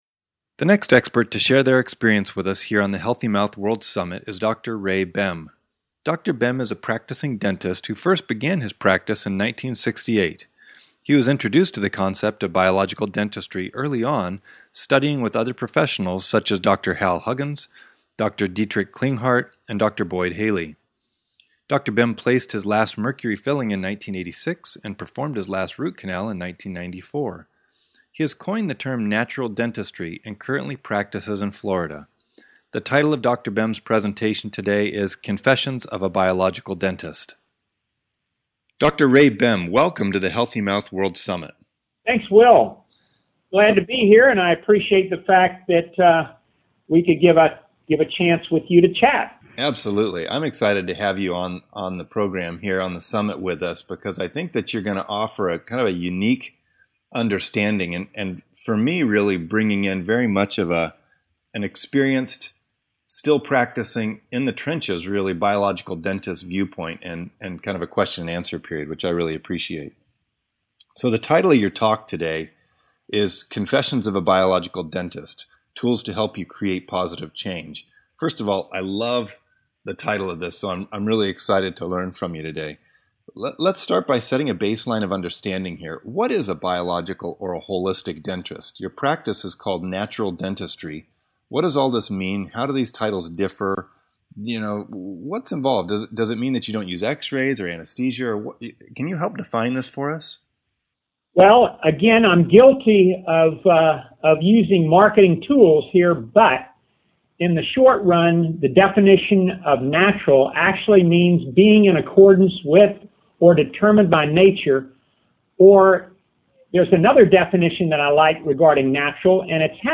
Expert Interview: Confessions of a Biological Dentist